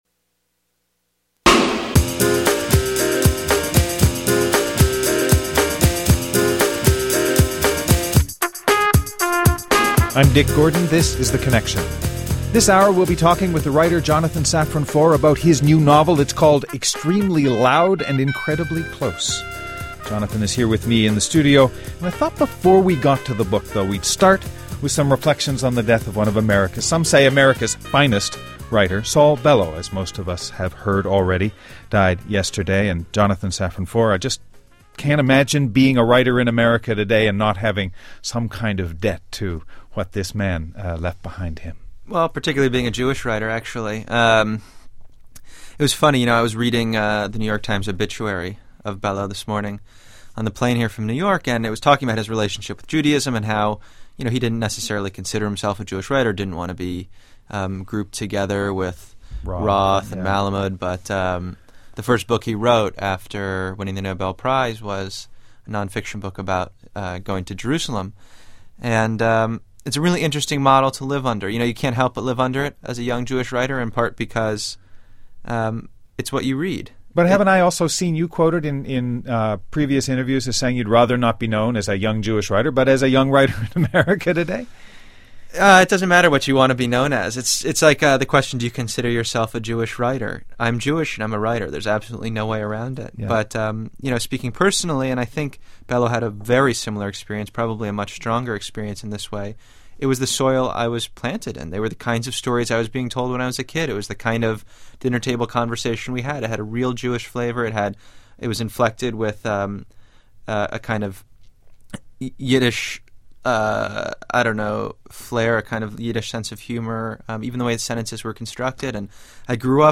A conversation about memory, meaning and writing 9/11 with Jonathan Safran Foer.